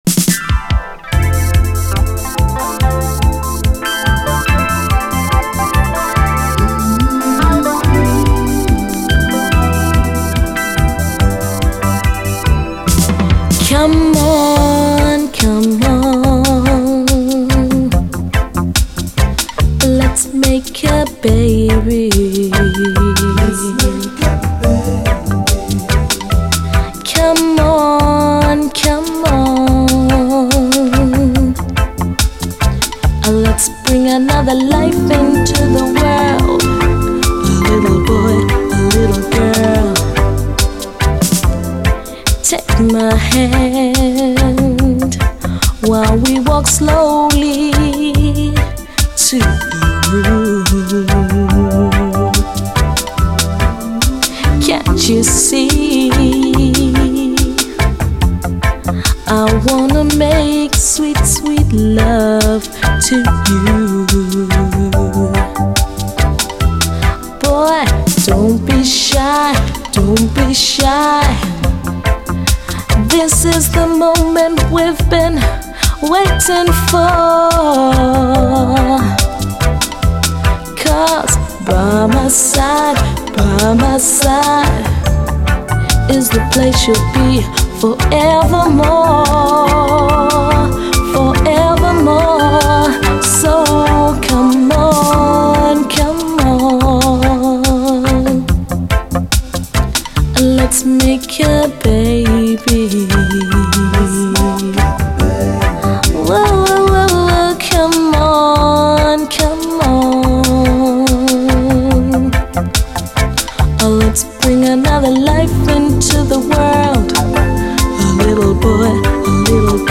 REGGAE
R&Bなんかとも相性がよさそうなモダンなやるせなさとメロウネス！後半のダブもお洒落です。